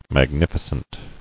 [mag·nif·i·cent]